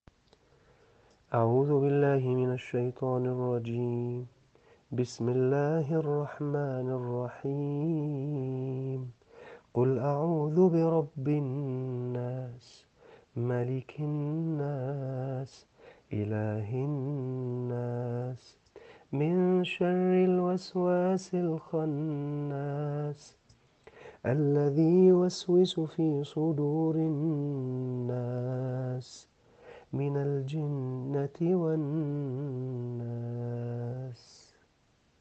Recitations
surah_nas.m4a